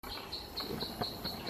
Arapasú (Dendrocincla turdina)
Nombre en inglés: Plain-winged Woodcreeper
Localización detallada: Sendero Macuco
Condición: Silvestre
Certeza: Vocalización Grabada